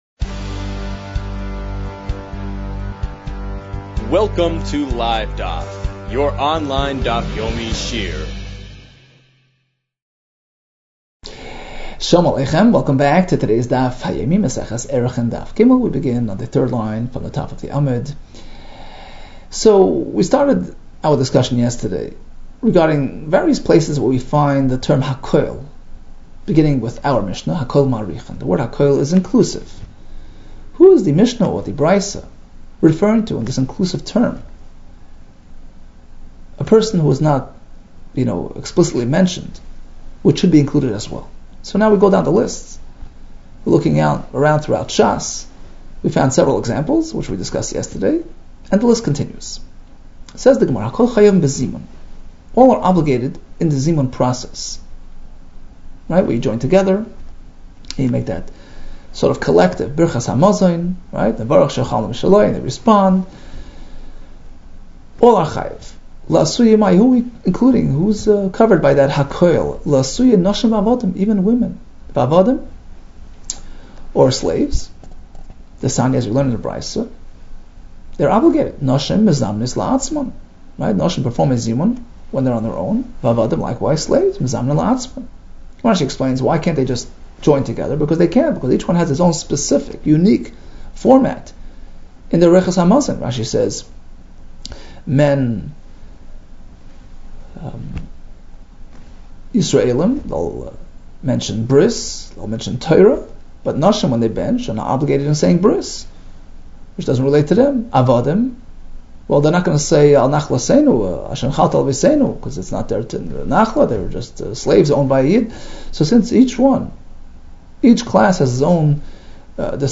Arachin 3 - ערכין ג | Daf Yomi Online Shiur | Livedaf